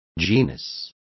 Complete with pronunciation of the translation of genus.